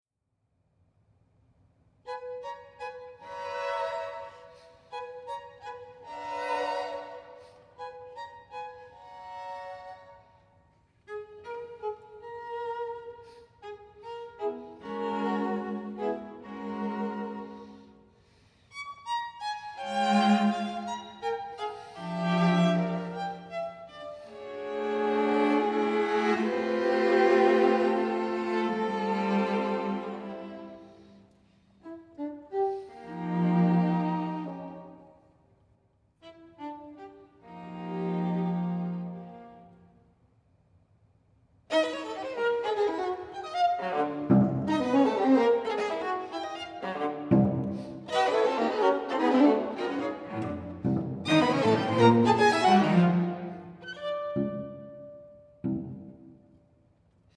Longtime Duke University string quartet in residence